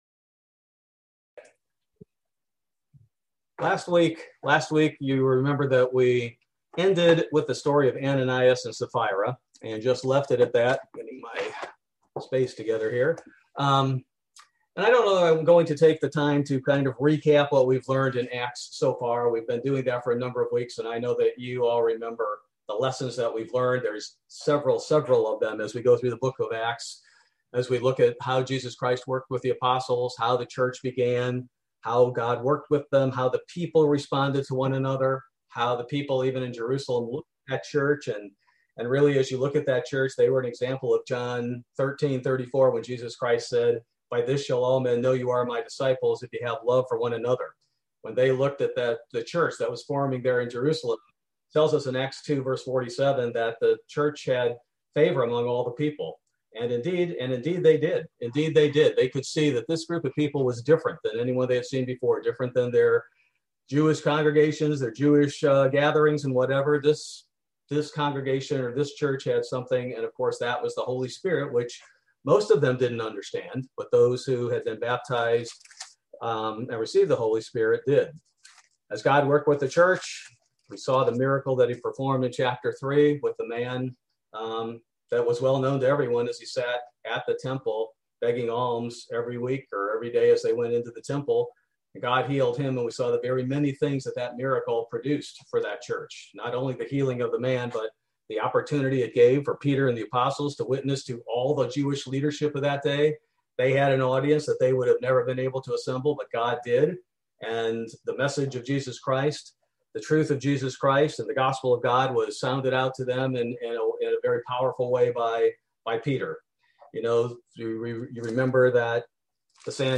Bible Study: June 9, 2021